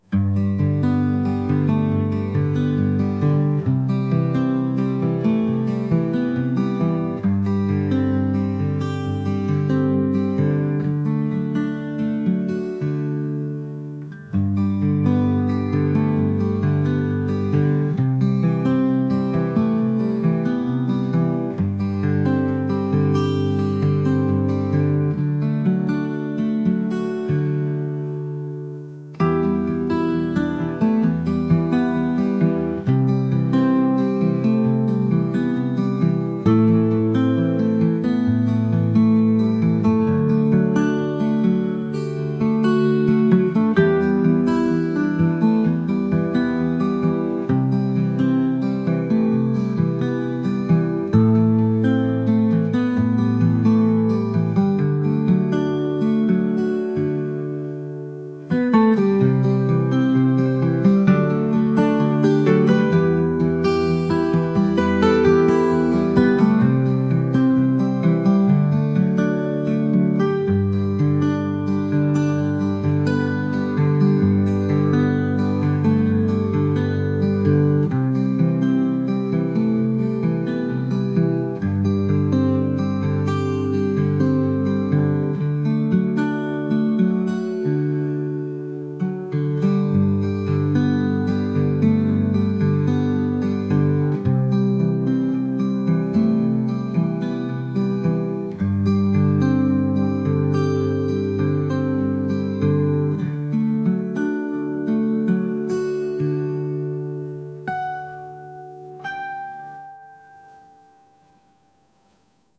indie | folk | ambient